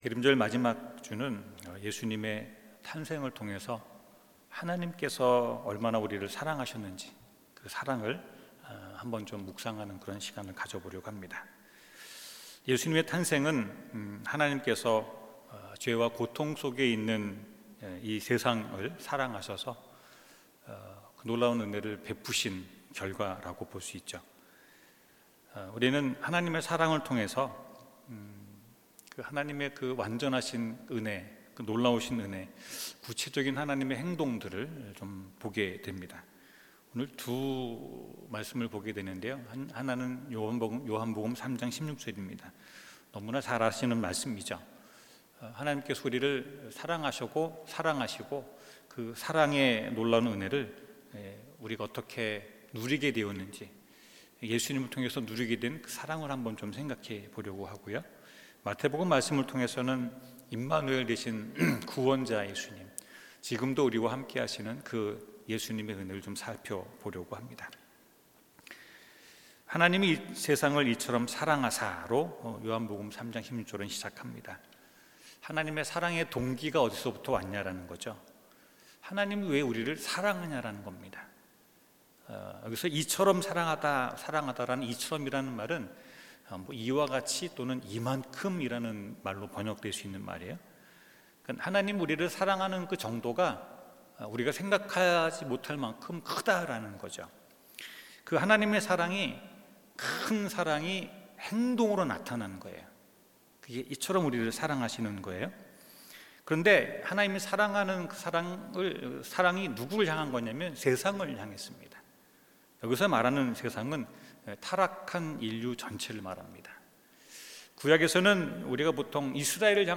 마태복음 1:21-23 설교